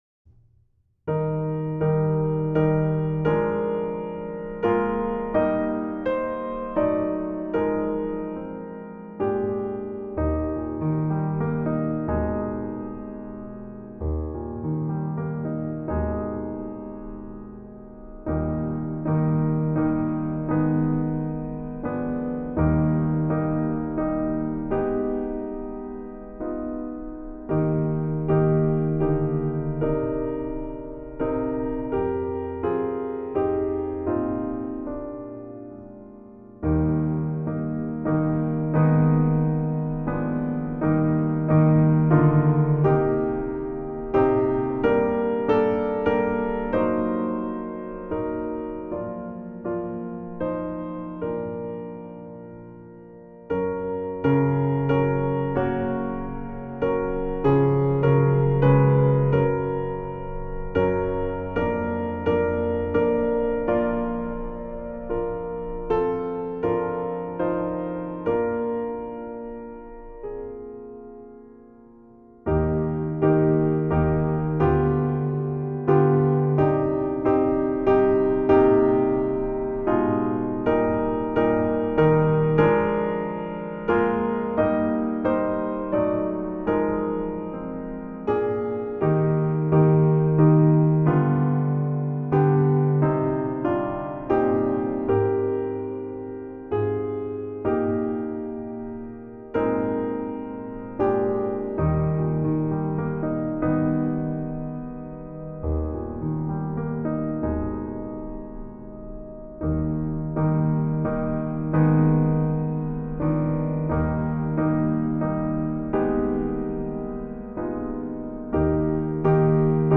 Choir Unison, SATB, Vocal Solo
Voicing/Instrumentation: SATB , Choir Unison